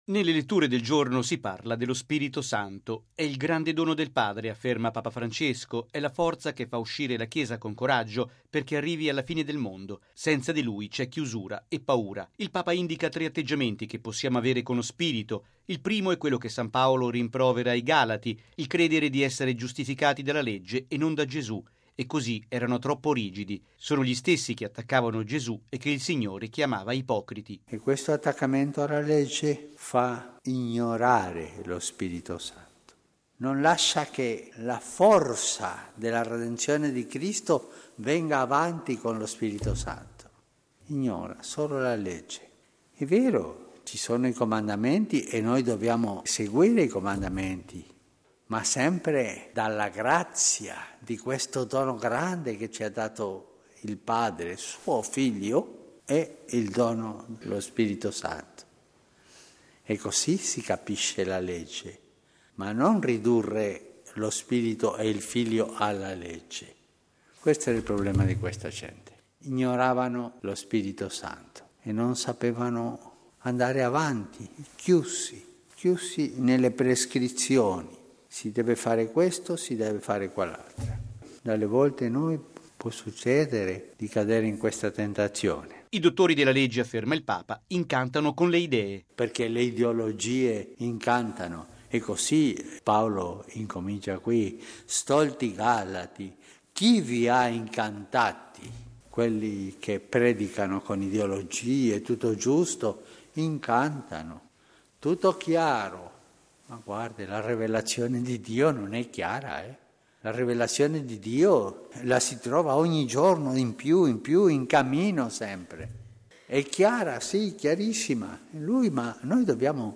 La vera dottrina non è rigido attaccamento alla Legge che incanta come le ideologie, ma è la rivelazione di Dio che si lascia trovare ogni giorno di più da quanti sono aperti allo Spirito Santo: è quanto ha detto il Papa nella Messa del mattino a Casa Santa Marta.